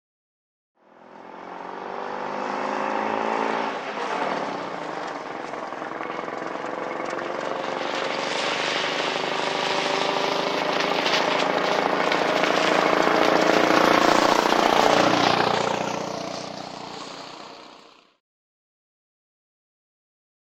Звук древнего фургона с еле работающим двигателем движется по дороге